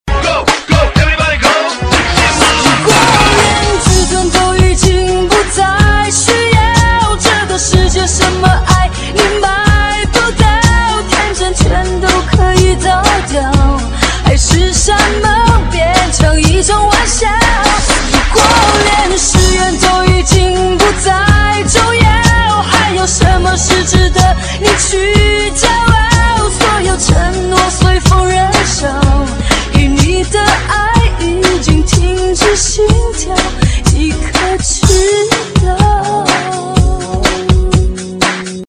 DJ铃声 大小
DJ舞曲